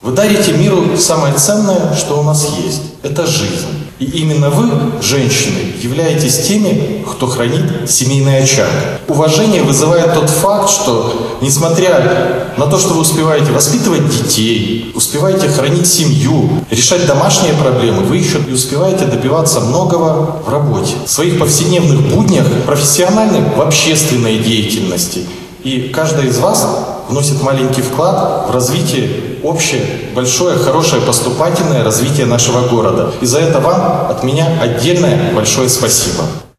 В преддверии Дня женщин большой зал Барановичского горисполкома наполнился  неповторимой атмосферой – яркой, праздничной, проникнутой уважением и благодарностью. Здесь состоялся торжественный прием, на который были приглашены прекрасные дамы – руководители и специалисты промышленности, образования, здравоохранения, культуры, многодетные мамы и бизнес-леди.
Обращаясь к приглашенным женщинам, председатель горисполкома Максим Антонюк подчеркнул, что этот праздник дает возможность высказать  слова глубокой благодарности и восхищения добротой, красотой и всем тем, чем славится настоящая белорусская женщина.